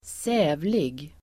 Ladda ner uttalet
Uttal: [s'ä:vlig]